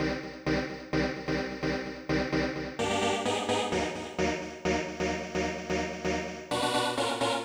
CLF Stab Riff Eb-C-Bb-Gb.wav